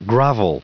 added pronounciation and merriam webster audio
757_grovel.ogg